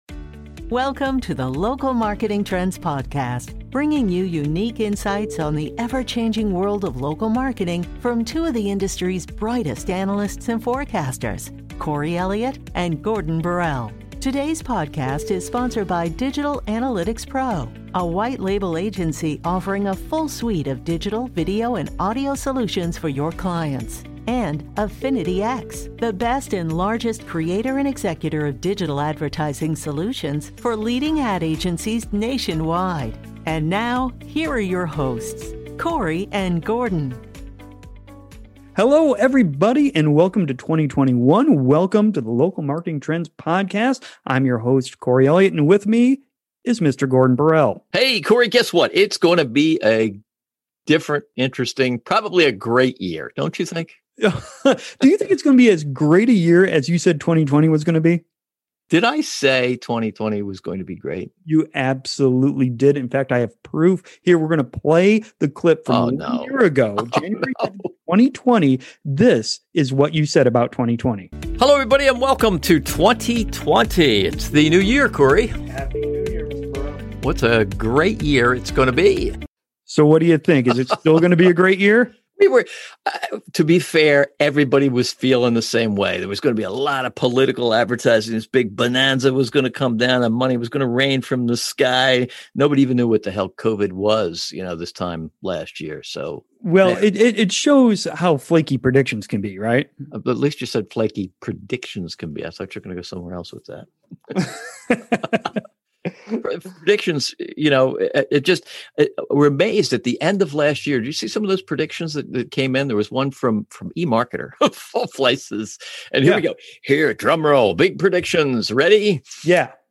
The podcast features an interview